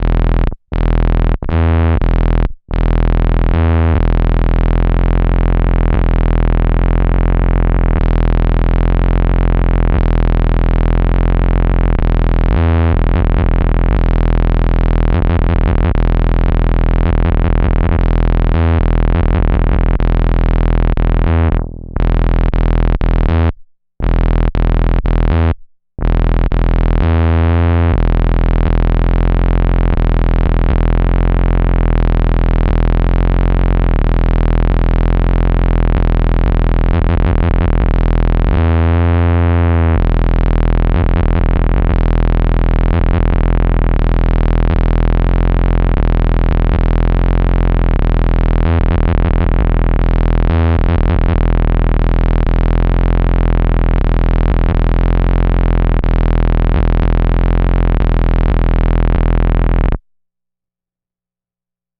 Experimenting with octaved notes and held octaves where either the low or high note is bent out of tune slightly to create the warbled sound.
Korg_G5_dissonant_octaves.mp3